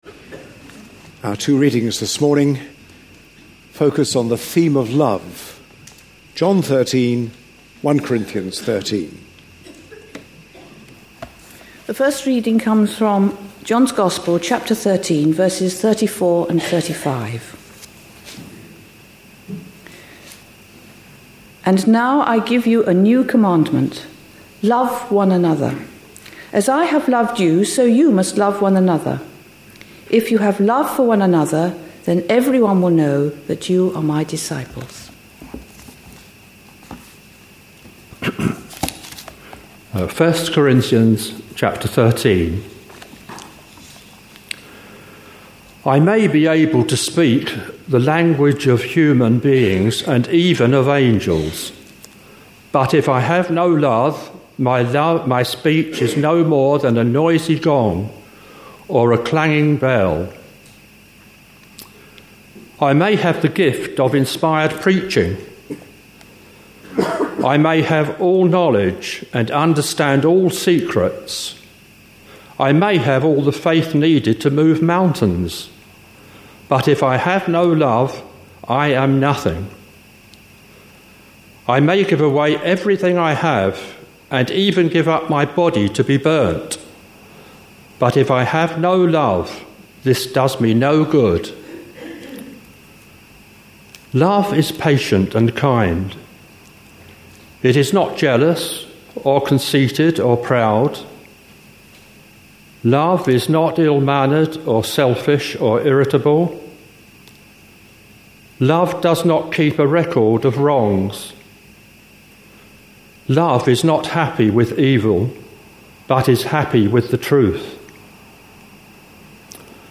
A sermon preached on 8th January, 2012, as part of our Looking For Love (10am Series) series.